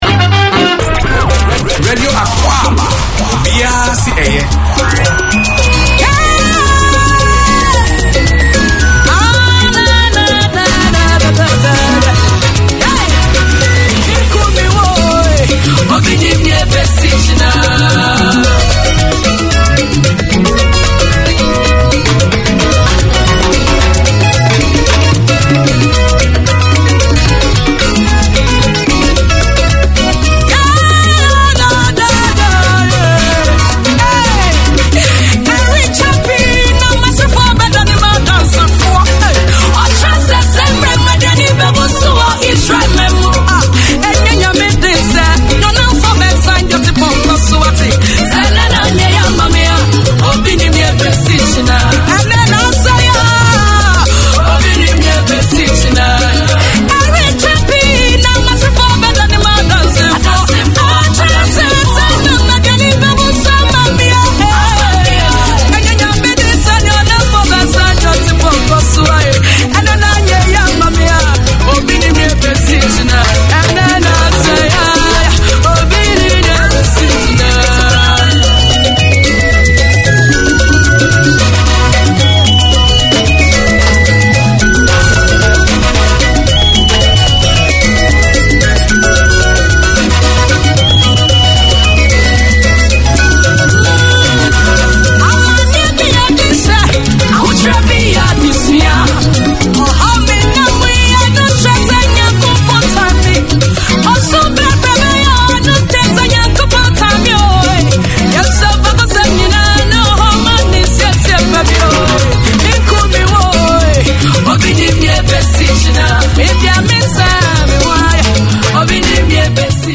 Radio Akwaaba is a programme targeted to the Ghanaian Community and all those who are interested in Ghana or African culture. It brings news, current affairs and sports reporting from Ghana along with music and entertainment.
Radio Akwaaba 8:30pm SATURDAY Community magazine Language: English Ghanaian Radio Akwaaba is a programme targeted to the Ghanaian Community and all those who are interested in Ghana or African culture.